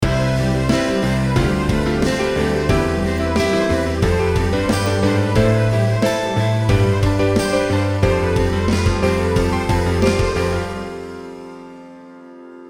Below, you can here two audio files: the first one is a guitar and then a drum without EQ, the second audio file is the same but then with EQ. Notice the difference and that the second track sounds more vivid and warm.